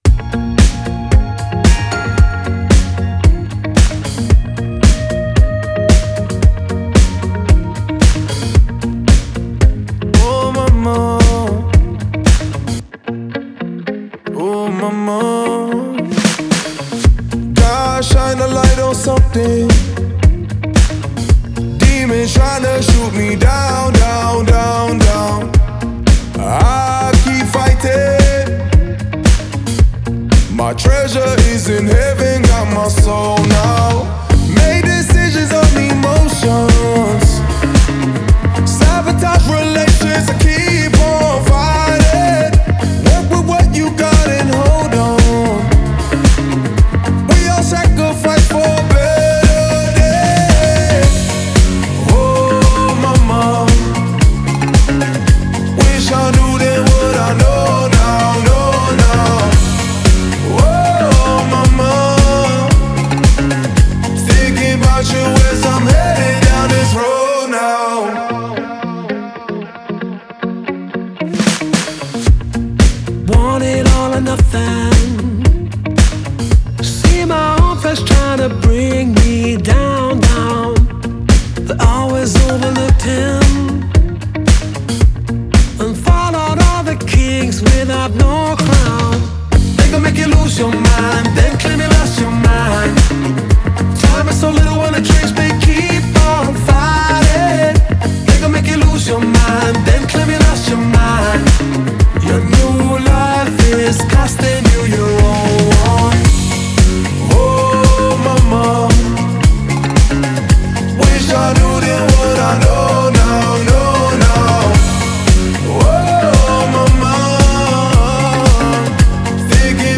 Genre: New Wave